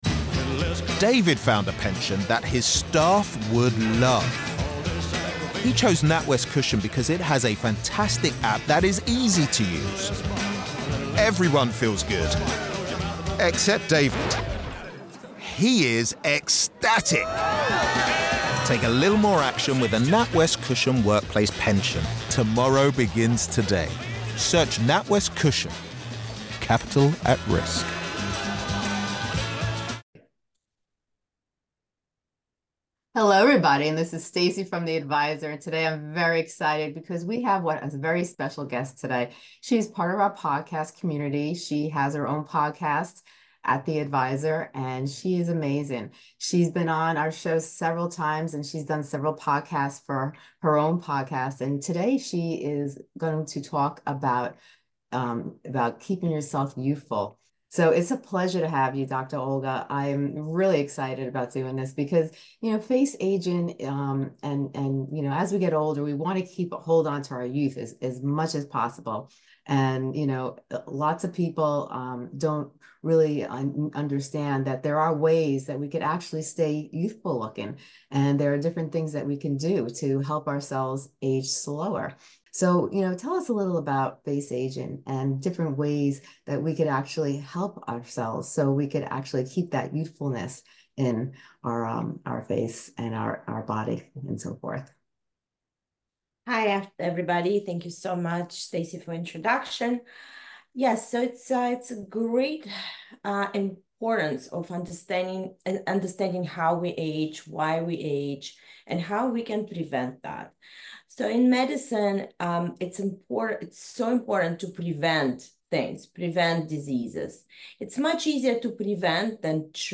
Explore non-surgical facelift techniques, injectables, and innovative treatments to maintain a youthful appearance for years. Say hello to a fresh, youthful face by discovering the transformative tips shared in this eye-opening discussion on aging and rejuvenation.